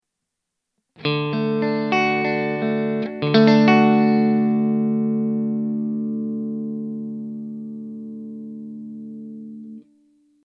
All audio clips are recorded with a Marshall JCM900 amp set with a clean tone.
Fender Stratocaster (3 Fender single coil pickups)
Clean sound, no effects added